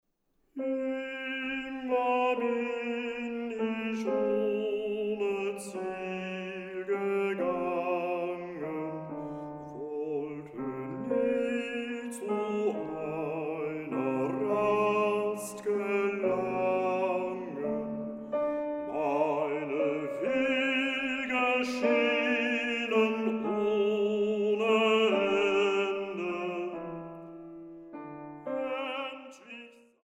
Bassbariton
Klavier